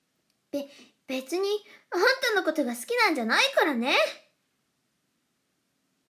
サンプルボイス ツンデレ 【少女】